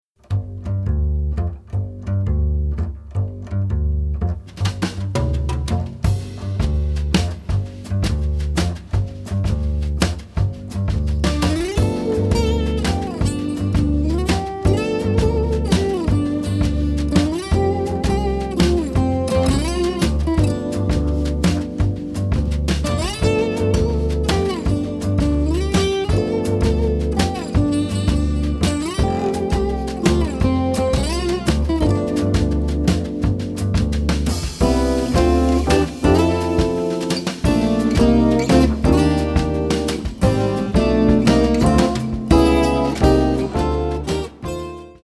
Also plays the piano and Fender Rhodes.
Chimes, congas, shaker and tambourine
Double bass
Drums 02.